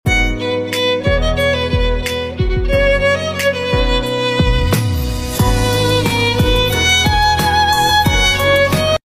Hahahaha🤣🤪 sound effects free download